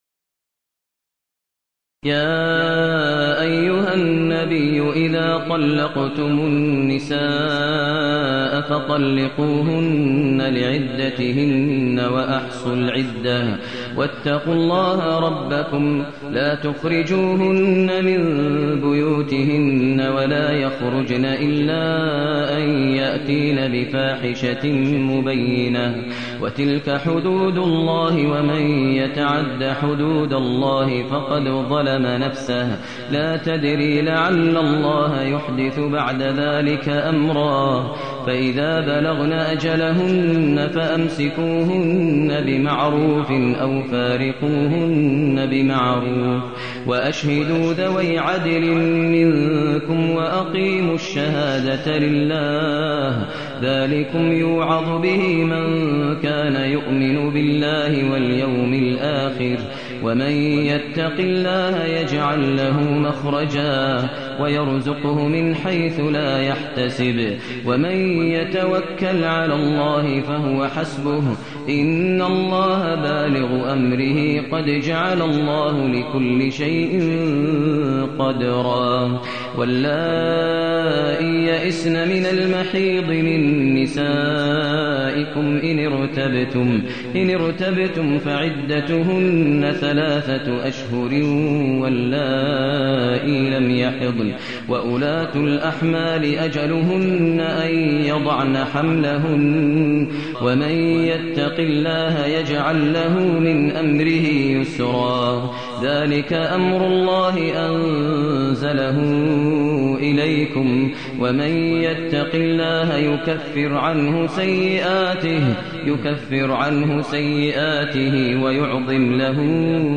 المكان: المسجد النبوي الشيخ: فضيلة الشيخ ماهر المعيقلي فضيلة الشيخ ماهر المعيقلي الطلاق